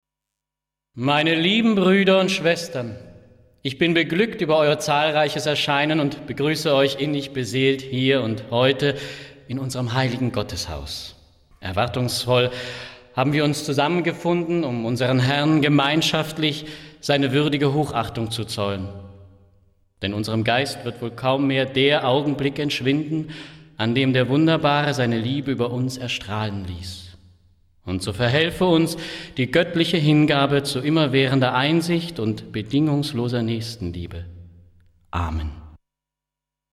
Stimmproben
Pfarrer.mp3